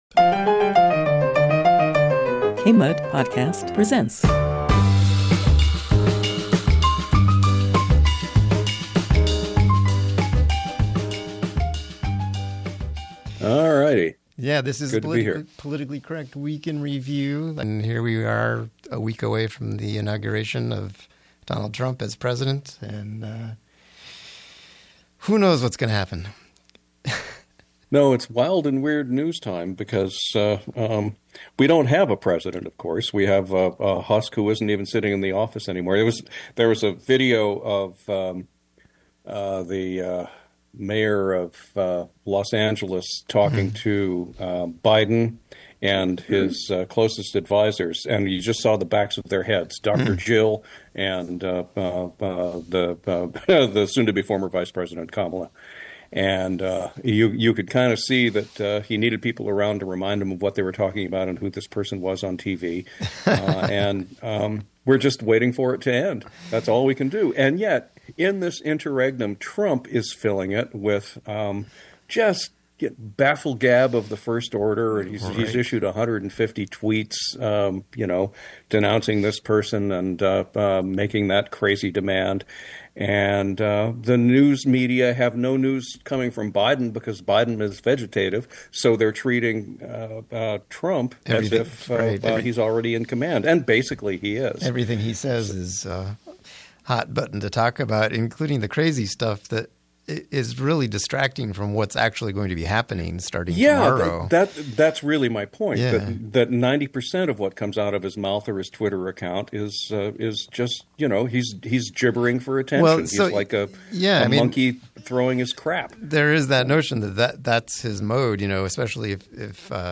From political absurdities to the wildfires raging in Los Angeles, in this episode we explore the coming inauguration and cabinet, environmental consequences of logging, climate catastrophes, and the intricate connections between capitalism, urban planning, and fire mismanagement. Callers weigh in on fire causes, conspiracy theories, and innovative solutions like private firefighting and zoning reform. Plus, we unravel the impact of algorithms, AI, and intrusive advertising on speech and information in the digital age.